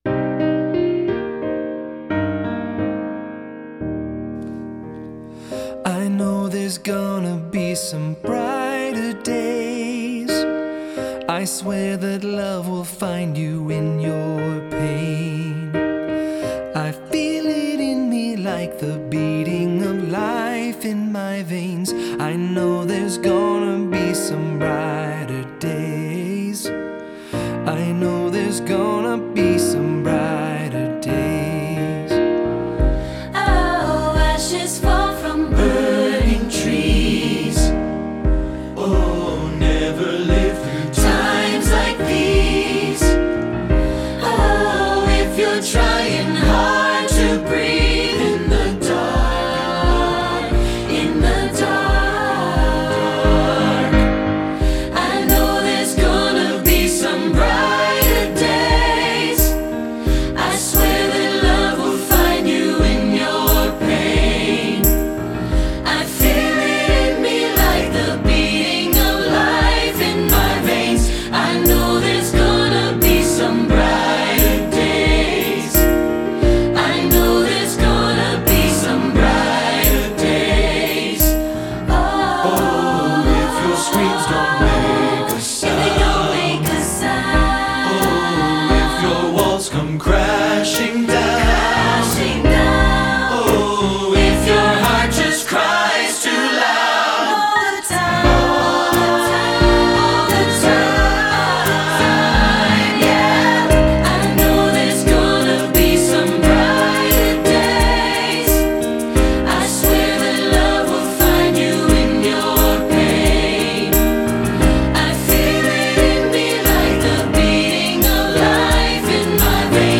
Choral Graduation/Inspirational Recent Pop Hits
SATB